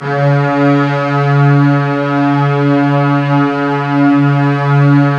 STR STRING08.wav